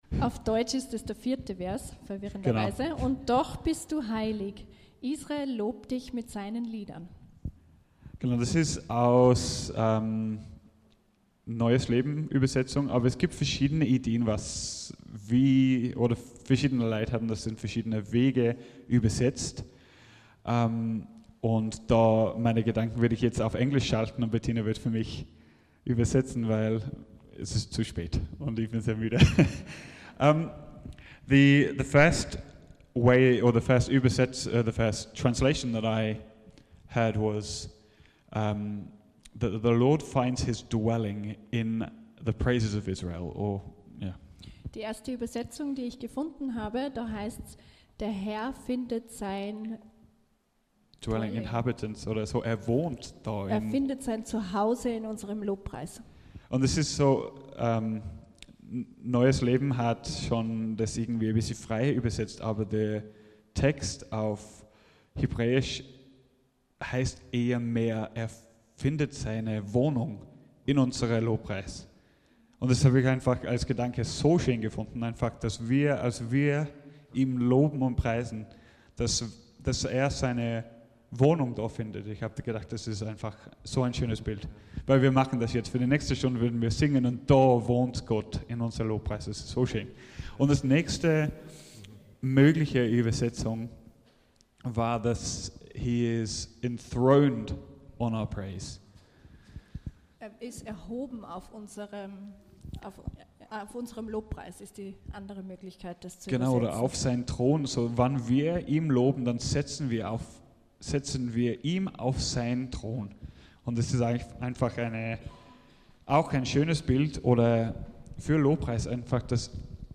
Lobpreisabend 18.10.2025 am Ende von 24-Stunden-Gebet